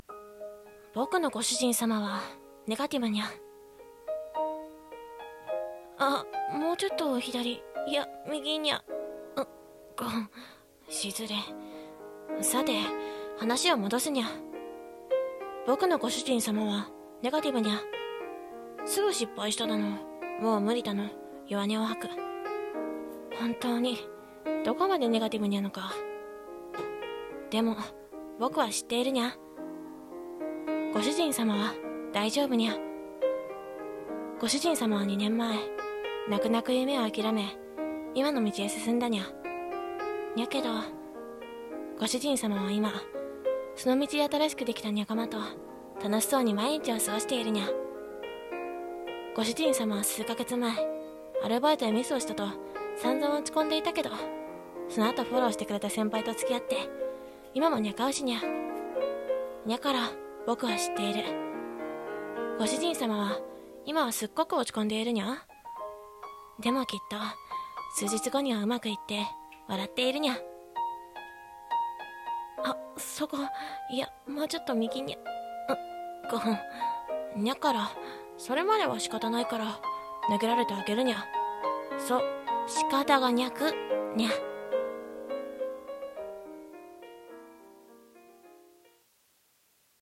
【朗読/台本】仕方にゃくにゃ